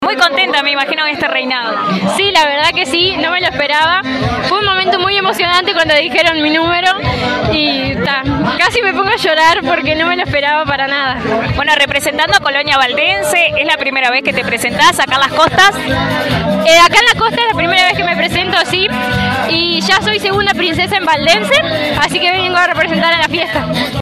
Escuchamos a la ganadora muy emocionada luego de ser coronada…